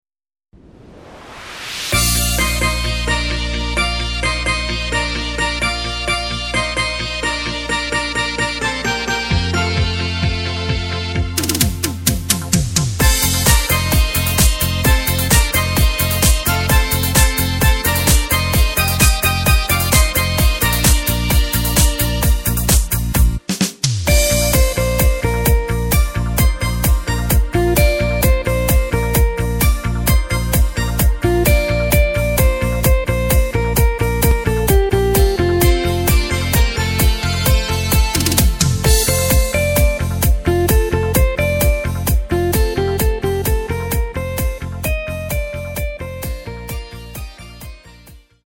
Takt:          4/4
Tempo:         130.00
Tonart:            Bb
Discofox (Cover) aus dem Jahr 2020!